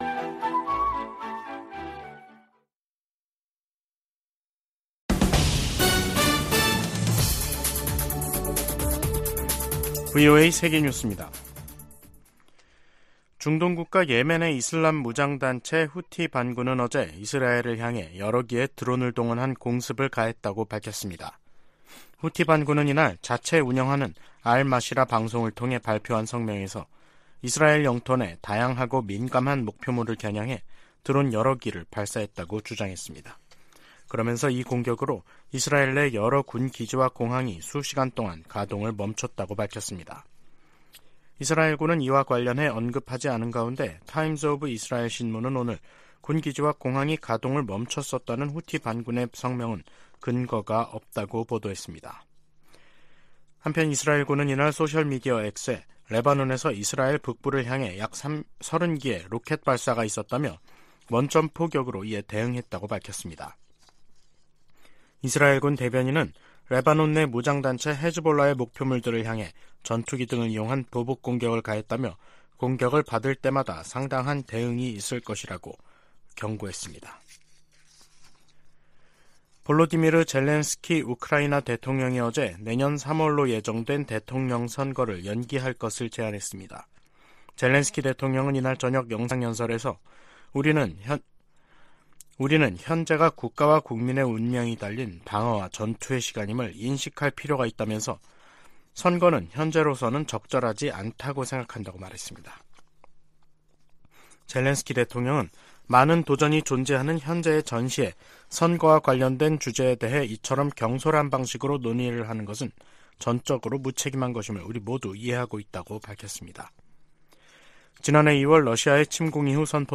VOA 한국어 간판 뉴스 프로그램 '뉴스 투데이', 2023년 11월 7일 2부 방송입니다. 미 국방부는 북한이 미한일 군사 협력에 연일 비난과 위협을 가하는데 대해, 이들 정부와 계속 협력해 나가겠다는 뜻을 거듭 밝혔습니다. 유럽과 중동의 두 개 전쟁이 한반도를 비롯한 아시아 안보도 위협하고 있다고 미 상원의원들이 밝혔습니다. 한국과 일본이 이달말 한중일 외교장관회담에서 북러 밀착에 대한 중국의 불안을 공략해야 한다고 미 전문가들이 말했습니다.